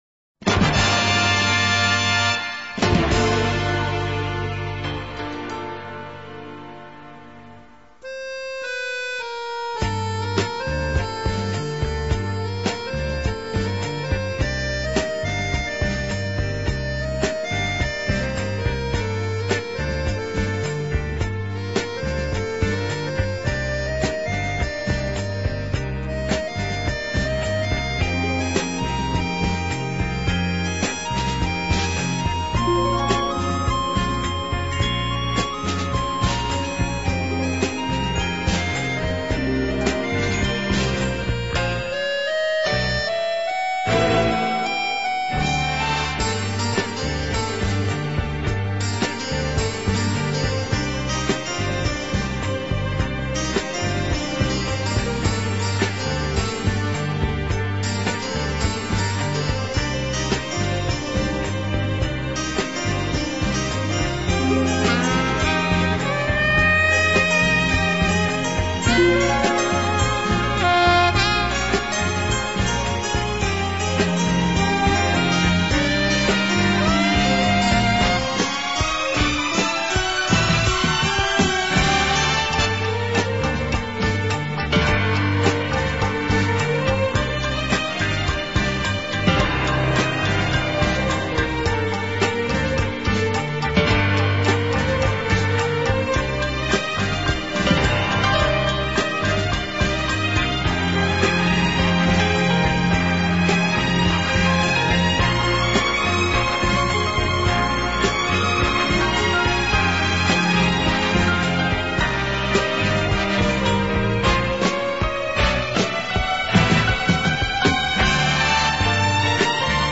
音樂類型: 演奏音樂